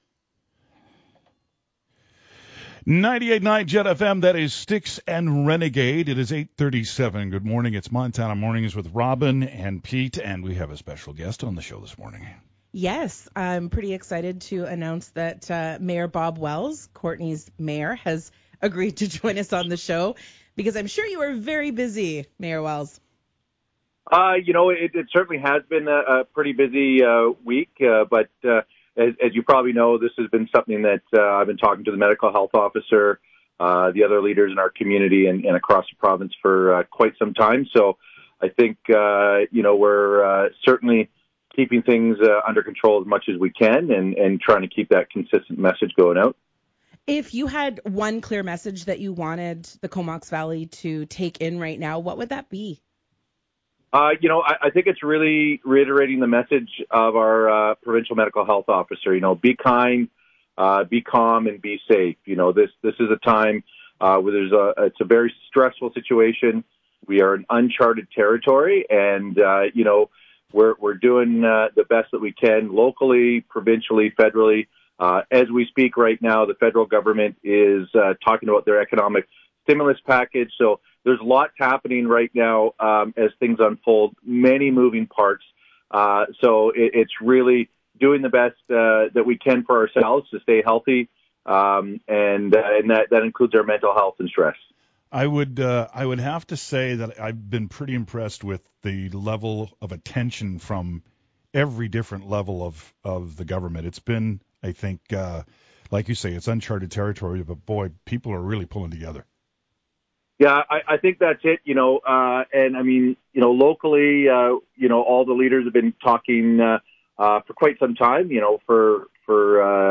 That person will be interviewed live during Montana Mornings